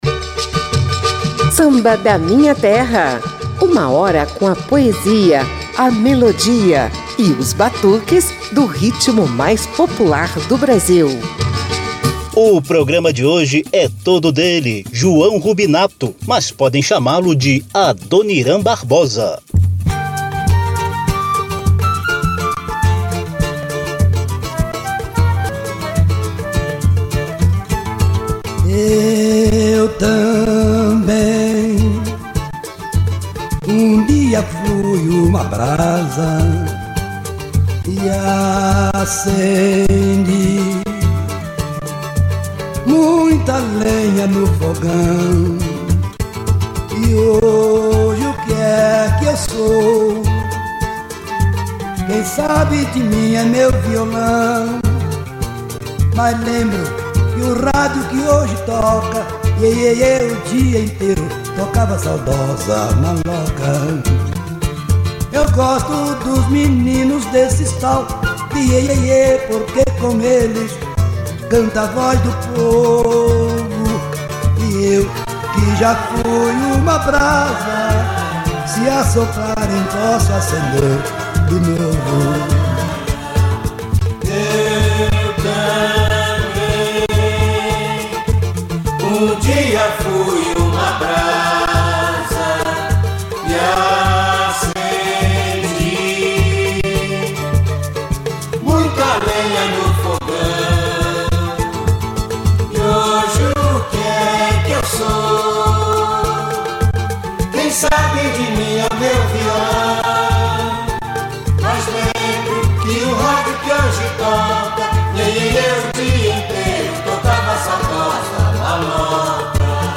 O quadro “papo de samba” resgata entrevistas históricas e frases bem humoradas do cantor e compositor paulistano.